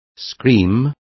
Complete with pronunciation of the translation of scream.